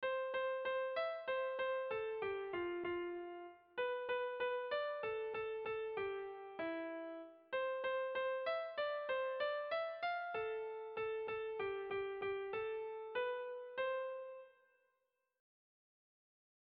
Erromantzea
Dima < Arratia-Nerbioi < Bizkaia < Euskal Herria
AB